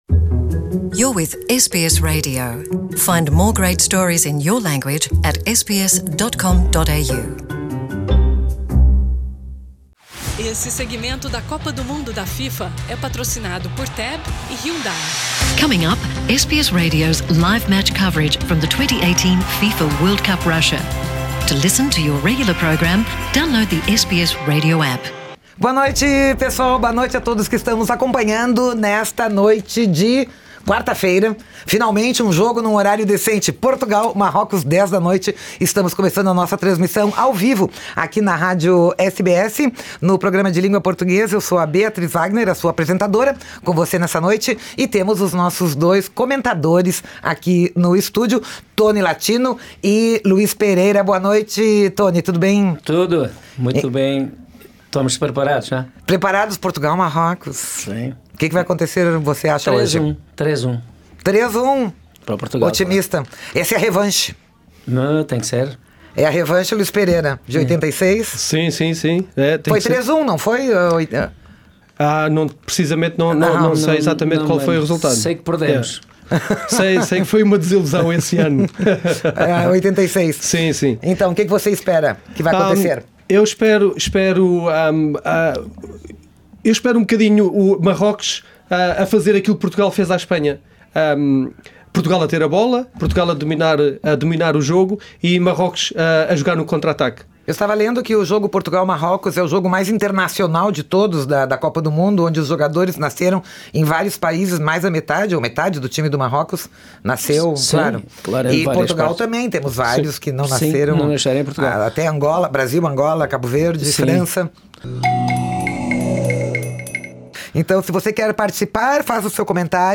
A SBS em Português transmite todos os jogos de Portugal e Brasil com narração da Antena 1 e Rádio Globo/CBN.